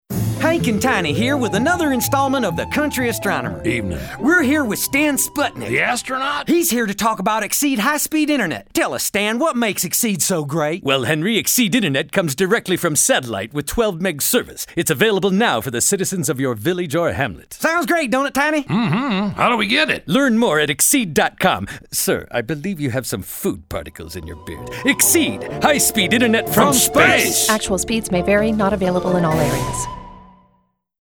We did several of these spots, featuring two country gentlemen who dabbled in astronomy but talked about internet service from space.